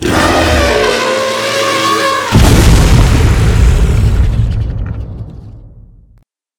die1.ogg